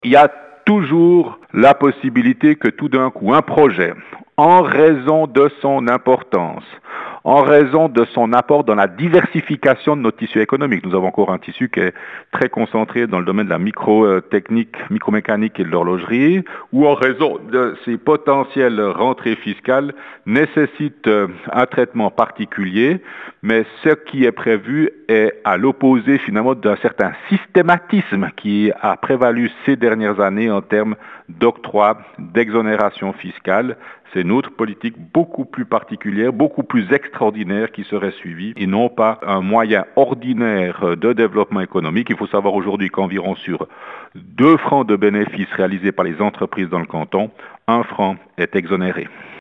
Jean Studer, ministre neuchâtelois des Finances